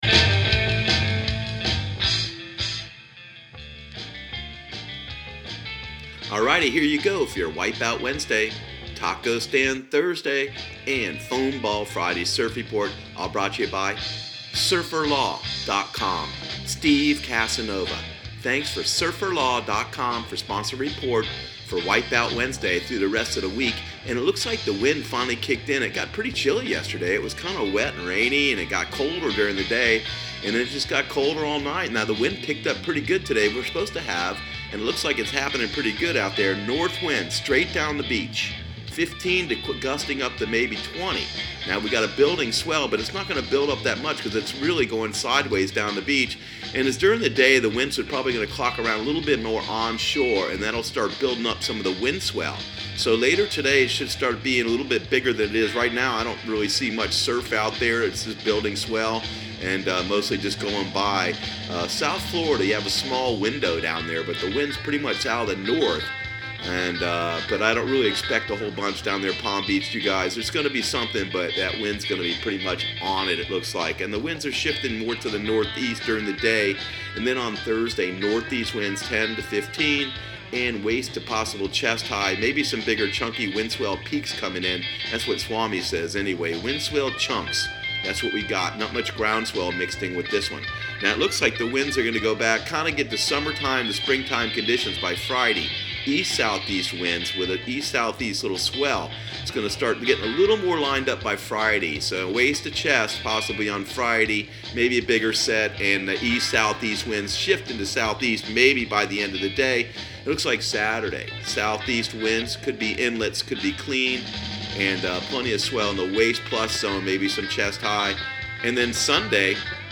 Surf Guru Surf Report and Forecast 03/06/2019 Audio surf report and surf forecast on March 06 for Central Florida and the Southeast.